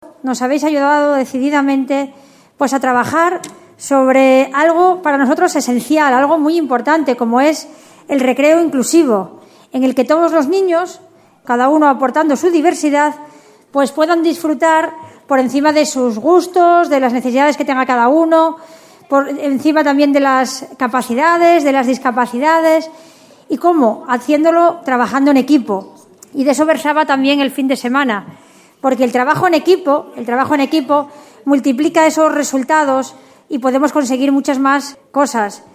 en la despedida del campamento que sirvió de homenaje a los ganadores.